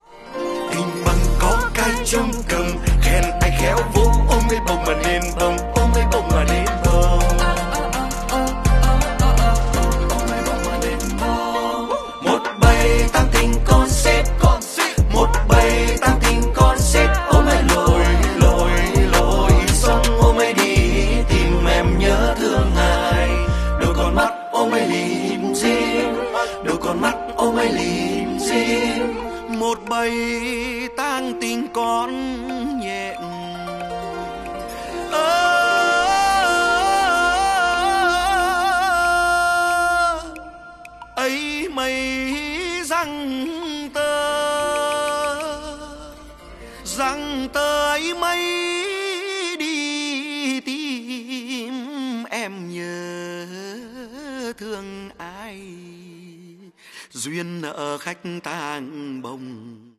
độc tấu đàn bầu